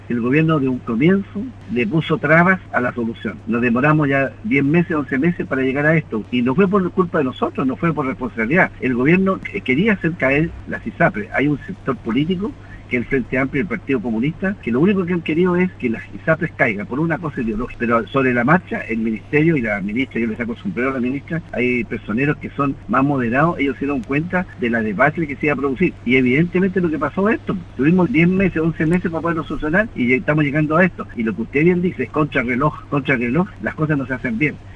En conversación con el programa “Primera Hora” de nuestra emisora, durante esta mañana, el parlamentario de la UDI dijo que aunar criterios en torno a este tema, es más fácil entre 10 personas y no entre todos los integrantes del Congreso.